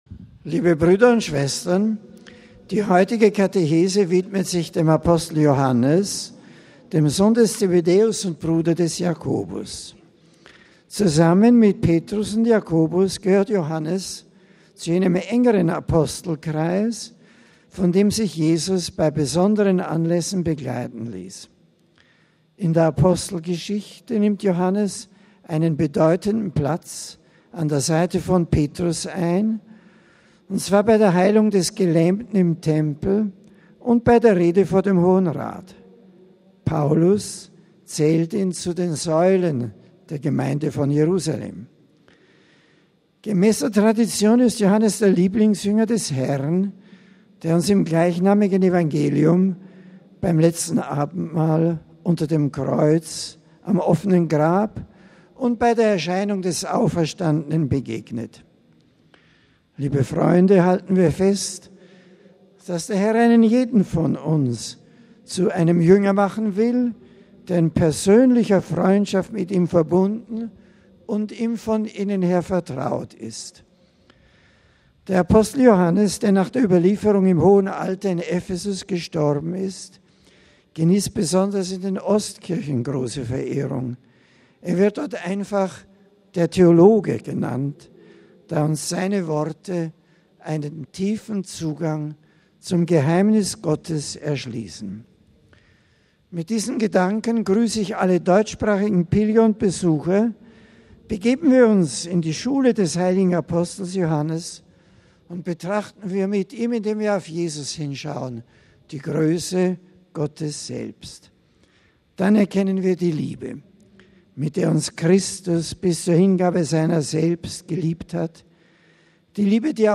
Vatikan: Generalaudienz des Papstes
MP3 Papst Benedikt XVI. bittet um mehr Rücksicht auf die Schöpfung. Gott habe sie dem Menschen anvertraut, damit dieser verantwortungsbewußt mit ihr umgehe, meinte der Papst heute bei seiner Generalaudienz auf dem Petersplatz.